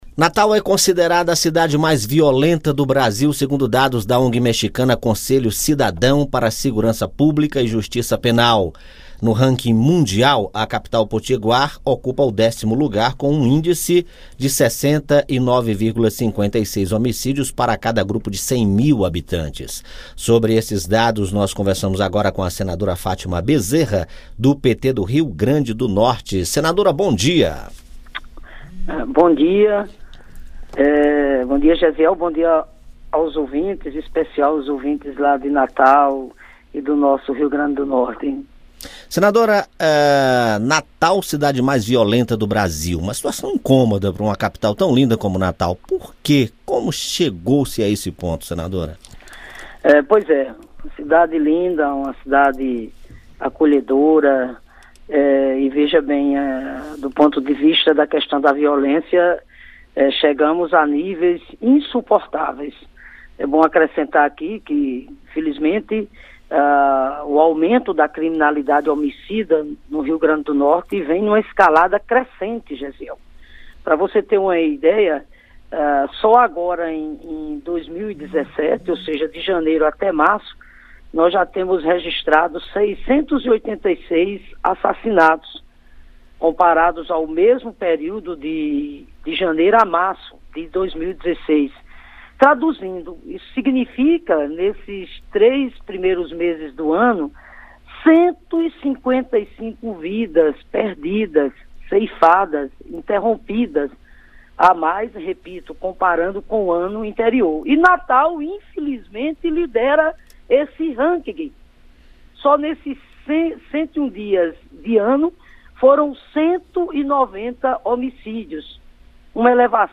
A senadora Fátima Bezerra (PT-RN) conversou